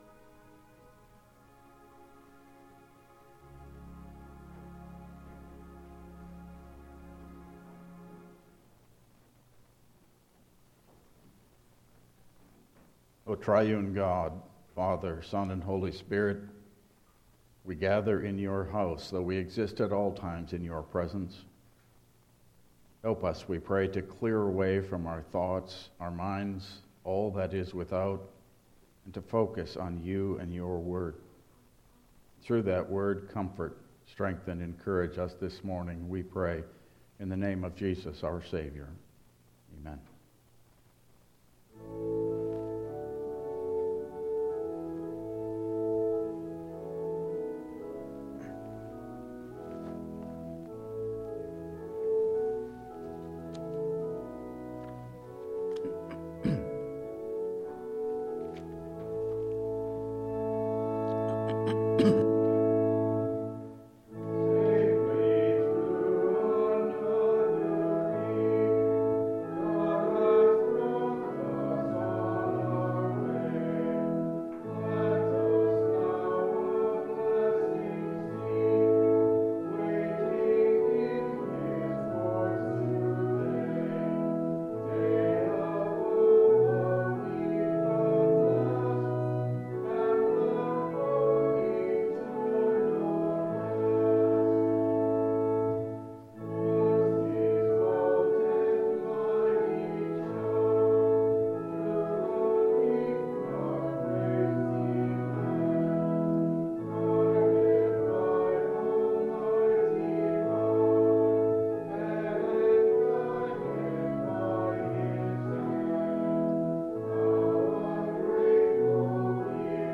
Passage: Jeremiah 23:19-32 Service Type: Regular Service